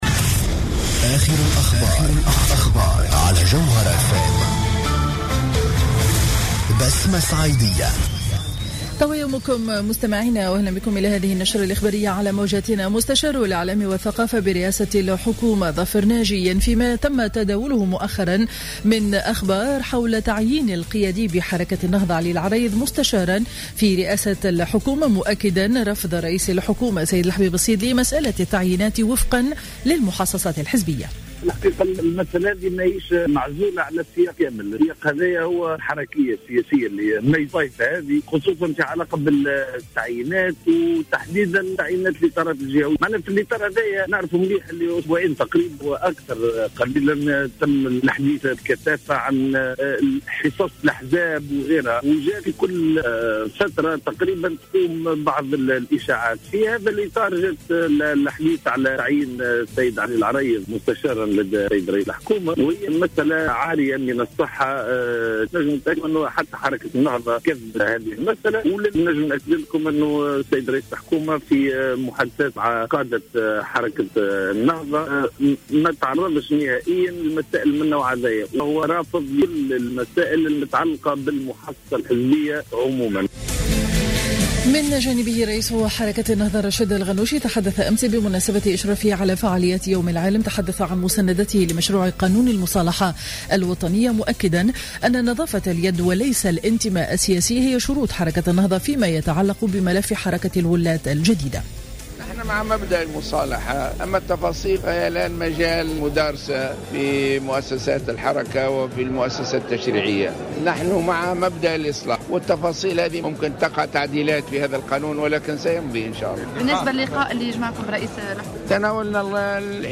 نشرة أخبار السابعة صباحا ليوم الإثنين 10 أوت 2015